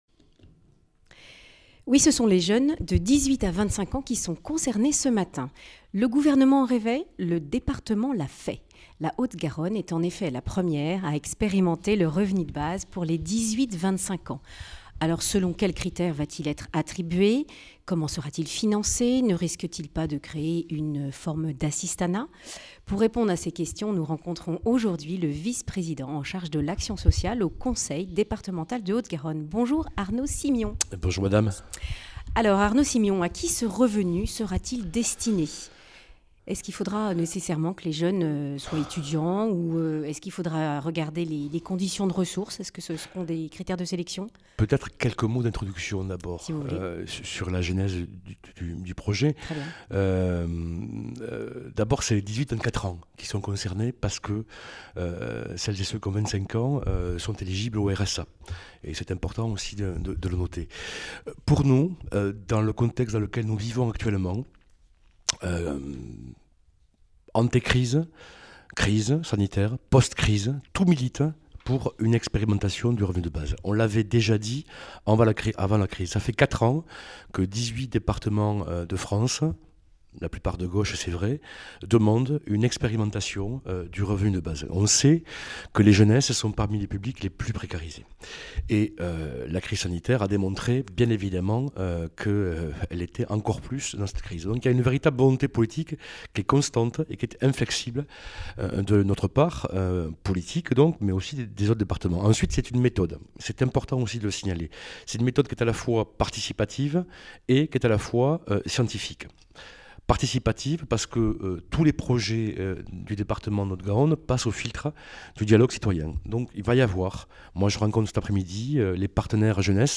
Un revenu de base pour les 18-24 ans est à l’essai au Conseil départemental de Haute Garonne. Arnaud Simion, son conseiller en charge de l’action sociale, explique en quoi consiste ce revenu de base.